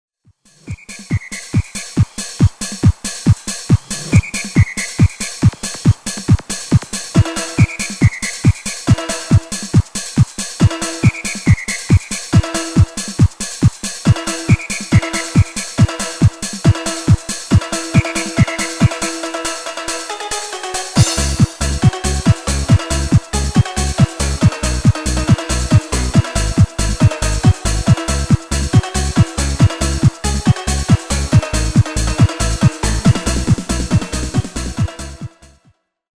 Demo's zijn eigen opnames van onze digitale arrangementen.